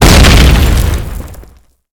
Explosion - Grenade sound 2